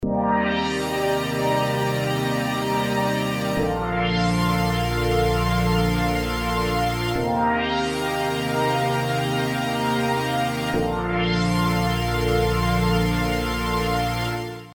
resonant pad